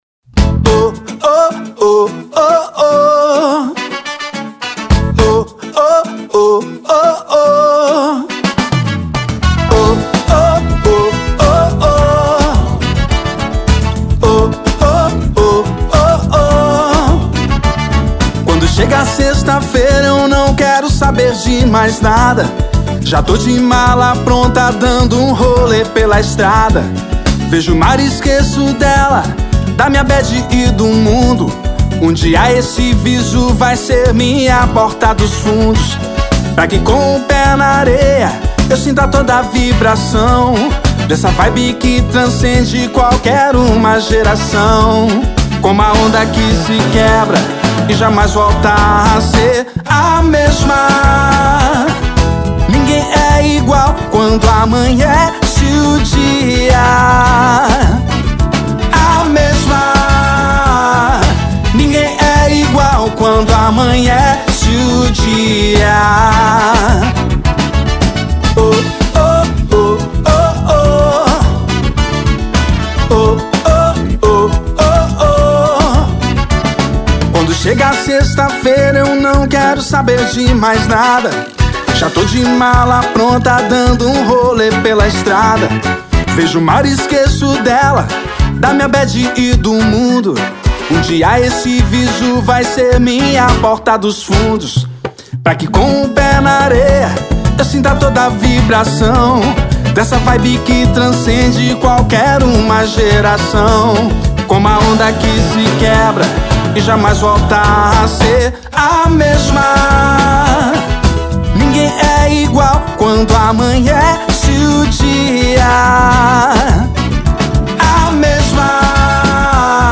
EstiloSamba Rock